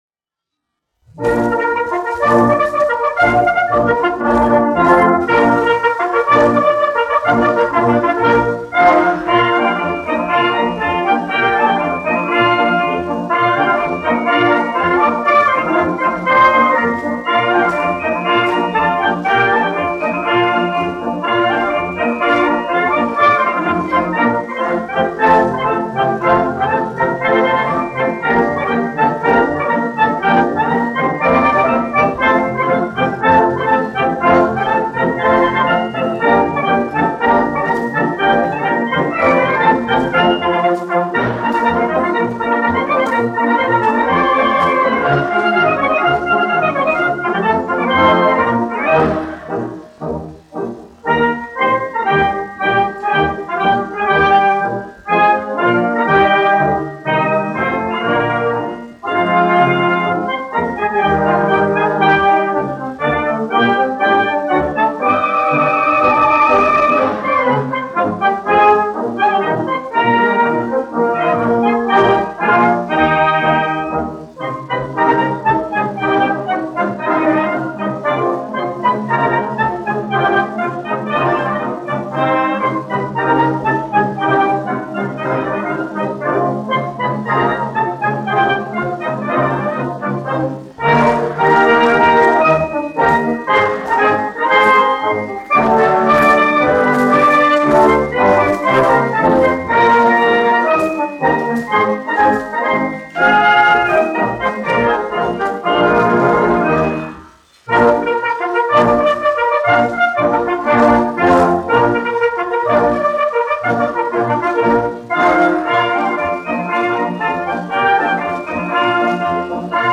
1 skpl. : analogs, 78 apgr/min, mono ; 25 cm
Pūtēju orķestra mūzika
Operas--Fragmenti, aranžēti
Skaņuplate
Latvijas vēsturiskie šellaka skaņuplašu ieraksti (Kolekcija)